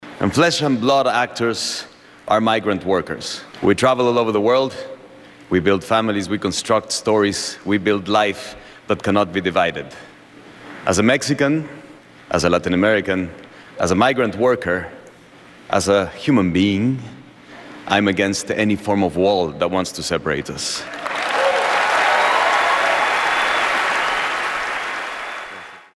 AUDIO Gael García Bernal en los Oscars «Me opongo al muro»